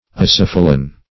Search Result for " acephalan" : The Collaborative International Dictionary of English v.0.48: Acephalan \A*ceph"a*lan\, n. Same as Acephal .